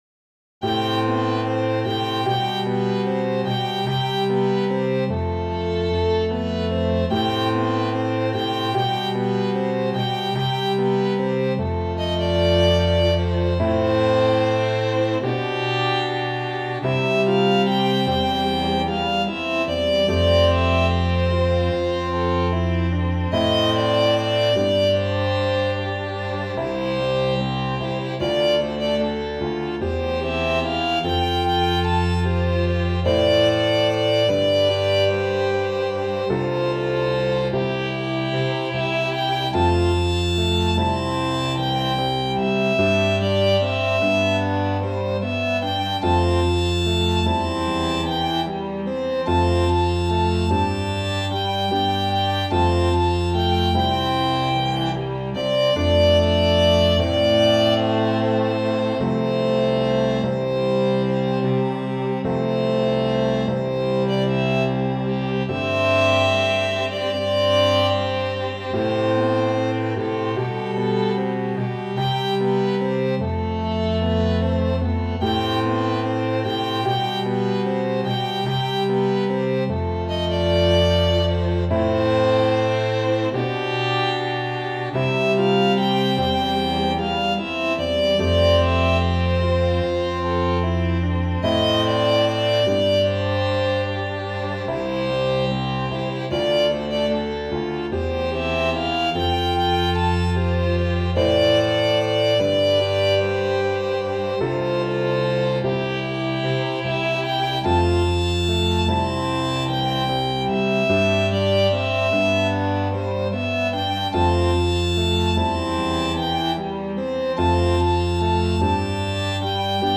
● Violino I
● Violino II
● Viola
● Violoncelo